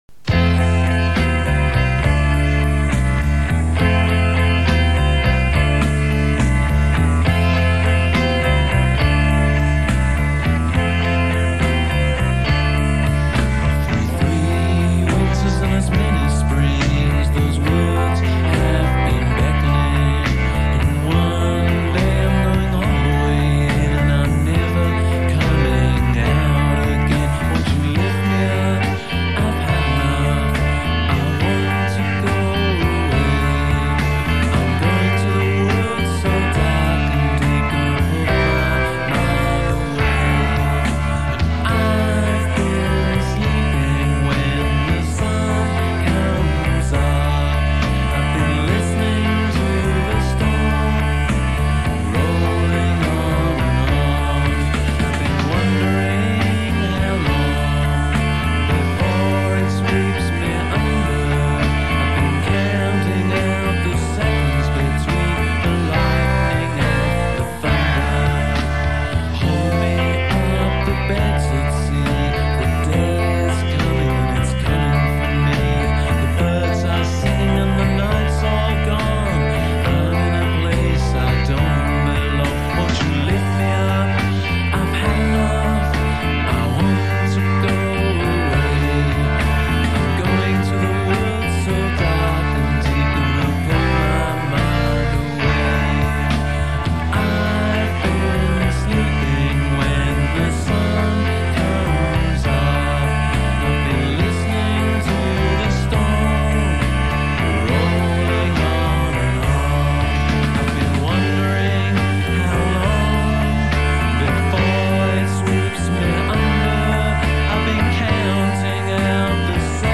vocals, guitar
drummer
bass